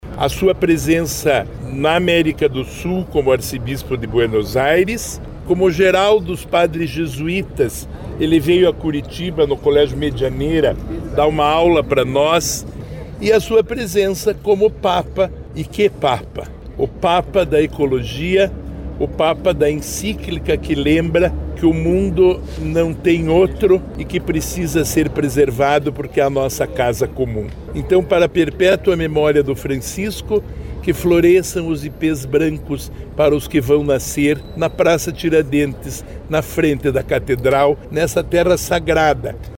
O atual secretário Estadual de Desenvolvimento Sustentável, Rafael Greca falou sobre a homenagem com o plantio de três ipês-brancos para recordar a presença extraordinária do Papa Francisco.